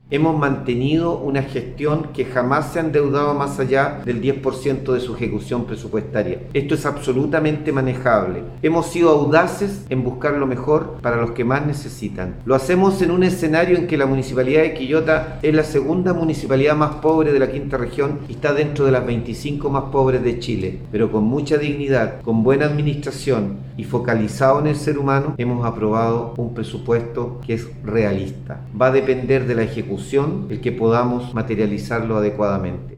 03-ALCALDE-MELLA-Una-deuda-manejable.mp3